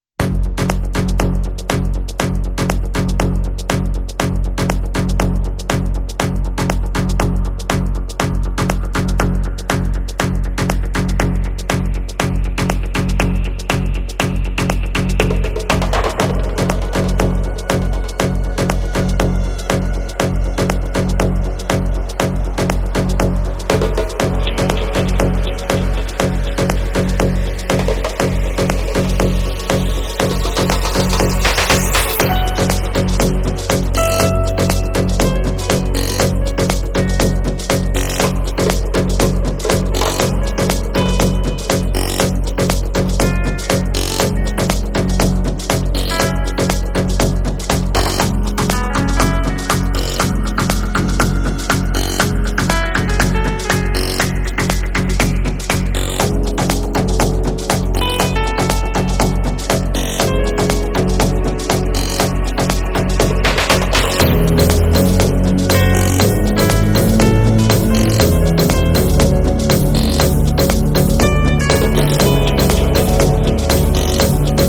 Foreign MusicSouth African
smooth and soulful vocals